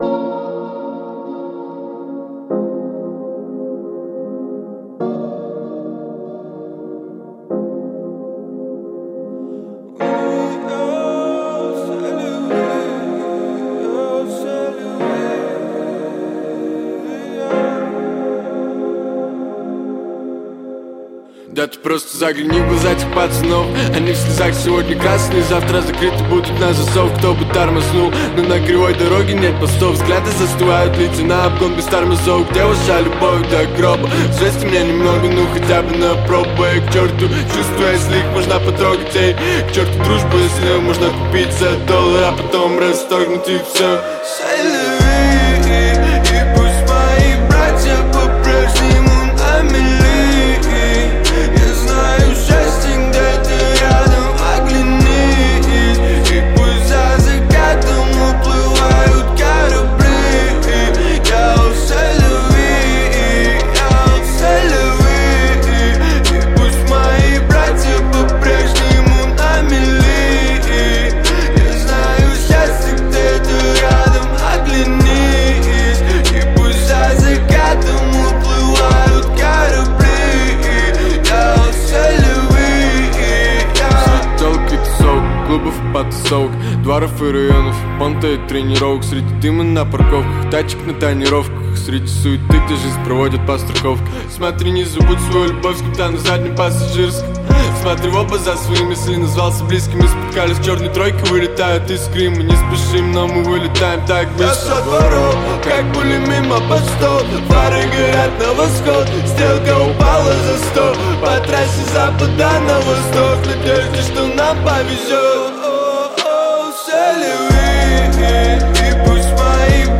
Жанр: Жанры / Русский рэп